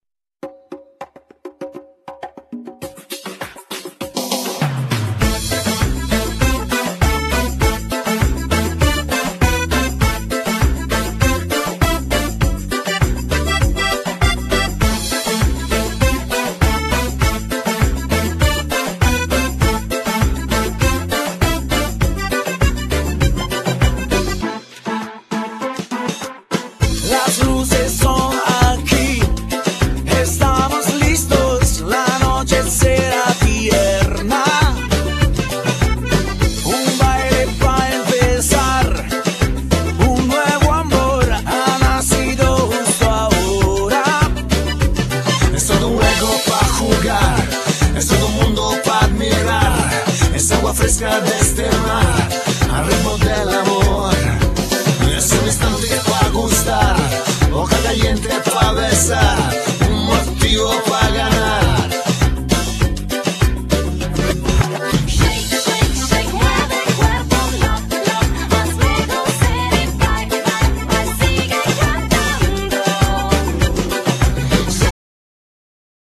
Genere : Pop Latin